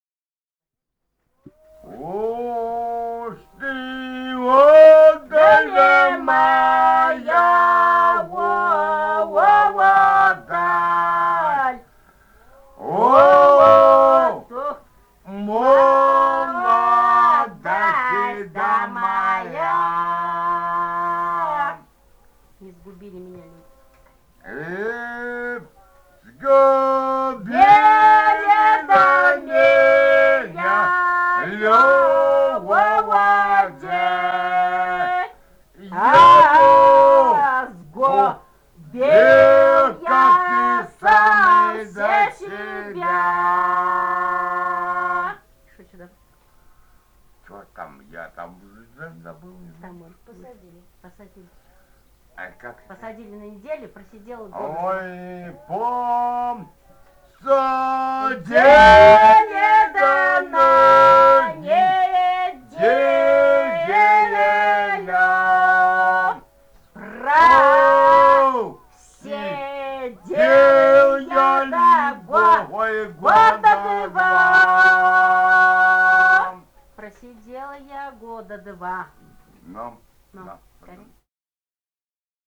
Этномузыкологические исследования и полевые материалы
«Уж ты удаль, моя удаль» (лирическая тюремная).
Бурятия, с. Харацай Закаменского района, 1966 г. И0905-04